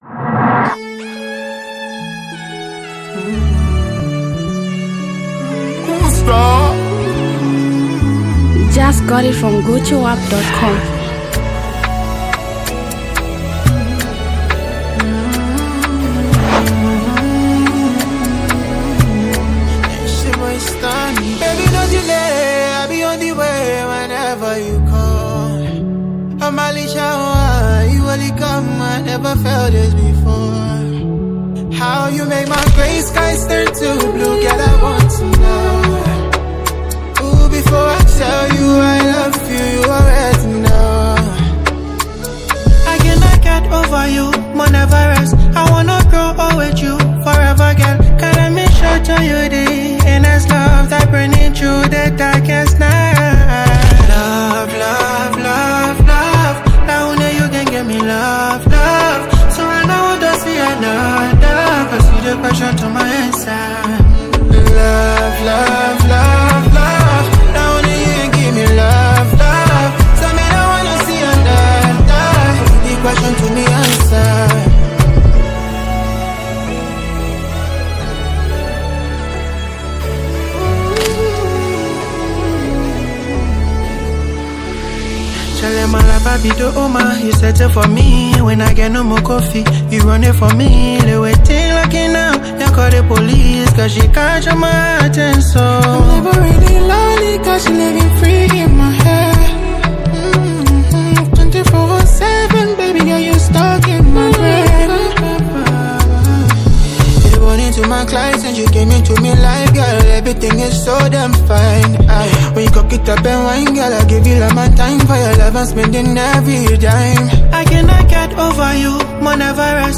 powerful melodic sound